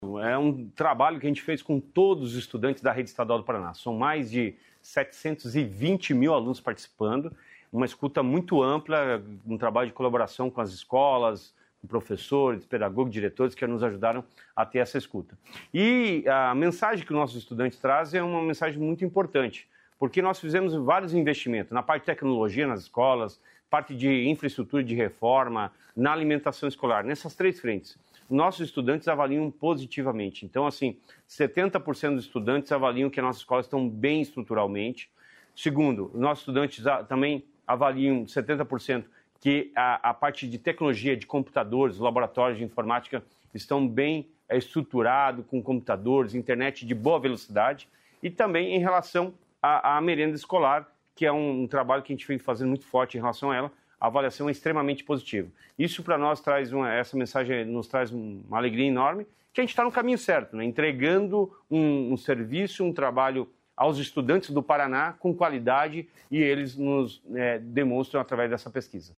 Sonora do secretário da Educação, Roni Miranda, sobre pesquisa das escolas estaduais